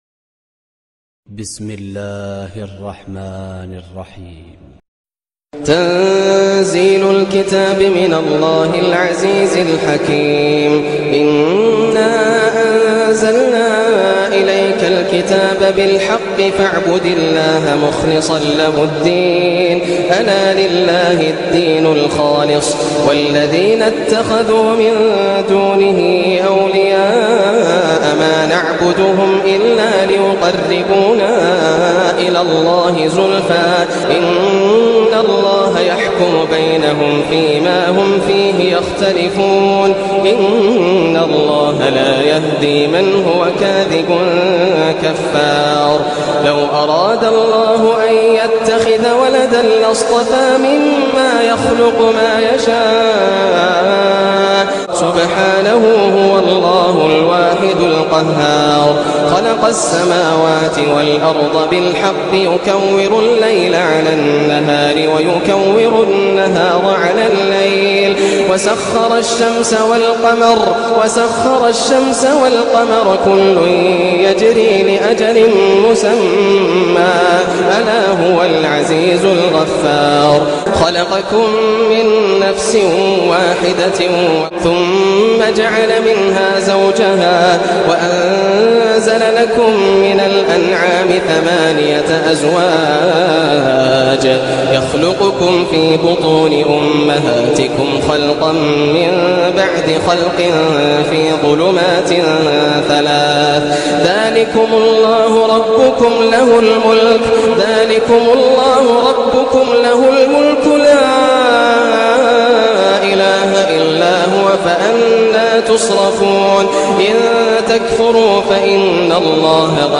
سورة الزمر > السور المكتملة > رمضان 1425 هـ > التراويح - تلاوات ياسر الدوسري